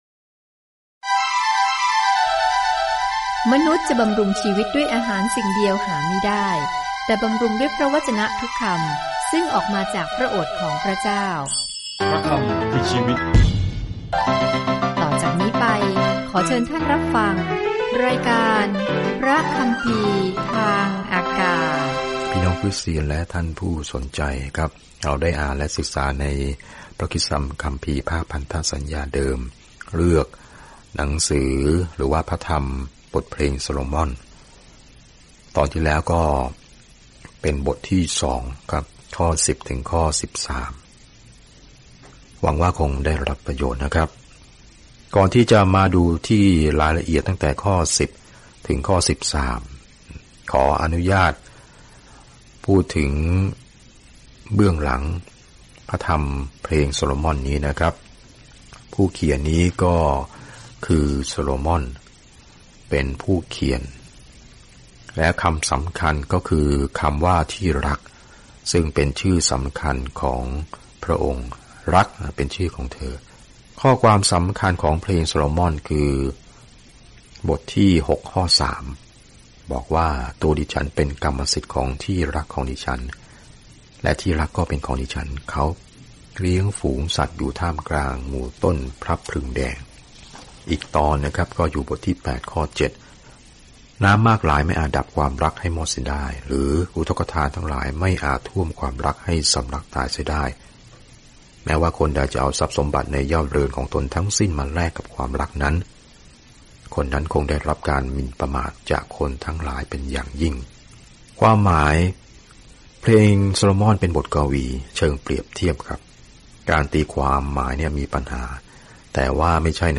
บทเพลงโซโลมอนเป็นเพลงรักสั้นๆ ที่เฉลิมฉลองความรัก ความปรารถนา และการแต่งงาน โดยเปรียบเทียบอย่างกว้างๆ ว่าพระเจ้าทรงรักเราในครั้งแรกอย่างไร เดินทางทุกวันผ่านเพลงโซโลมอนในขณะที่คุณฟังการศึกษาด้วยเสียงและอ่านข้อที่เลือกจากพระวจนะของพระเจ้า